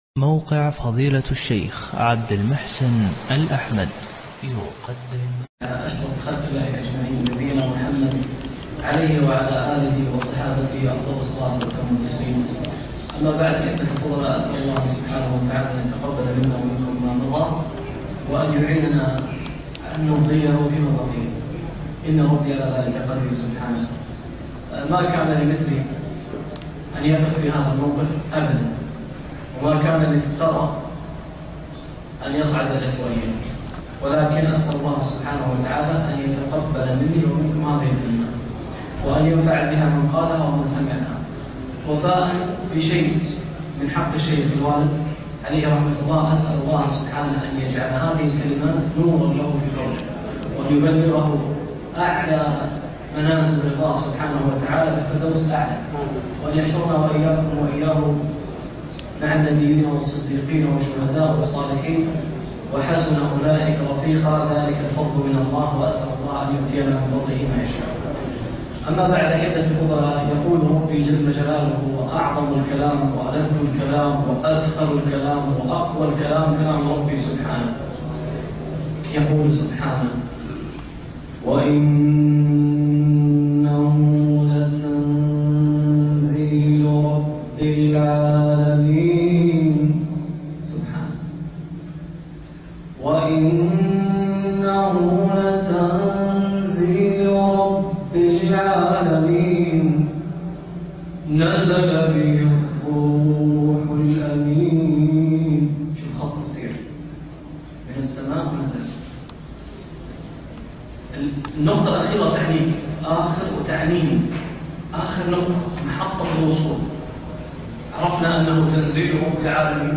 كلمة فى الأفطار الرمضانى لآل باز